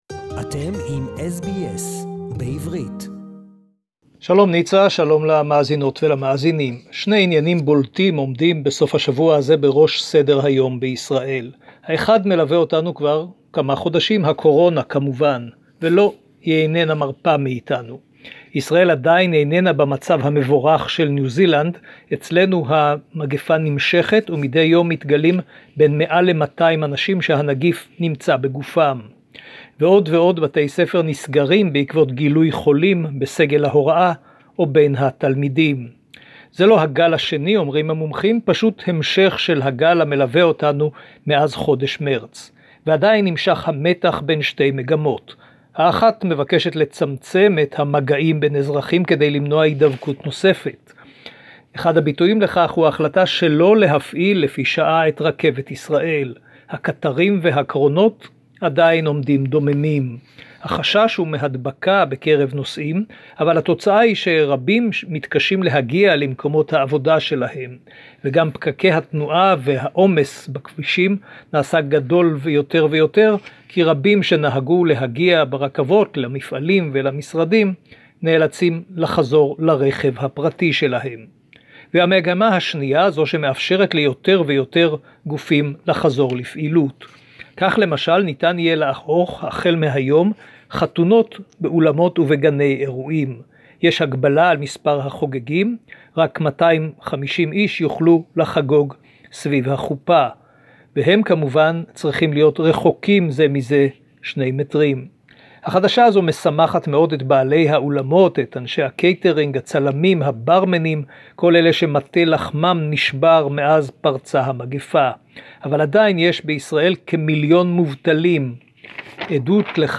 reporting from Jerusalem